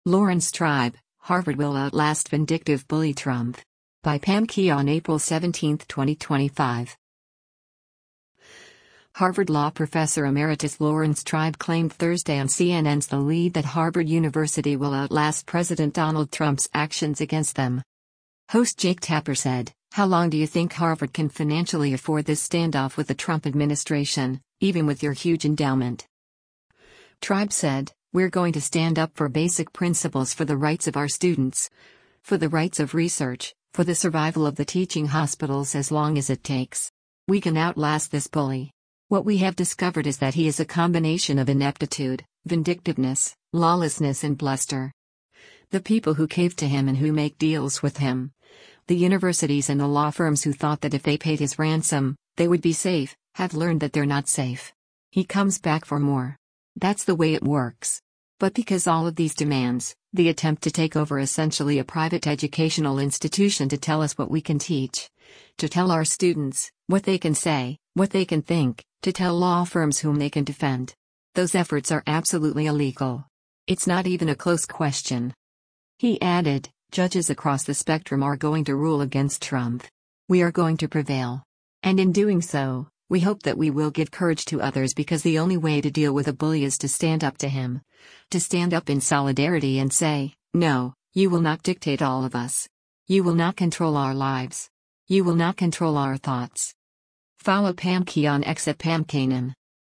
Harvard Law professor emeritus Laurence Tribe claimed Thursday on CNN’s “The Lead” that Harvard University will “outlast” President Donald Trump’s actions against them.
Host Jake Tapper said, “How long do you think Harvard can financially afford this standoff with the Trump administration, even with your huge endowment?”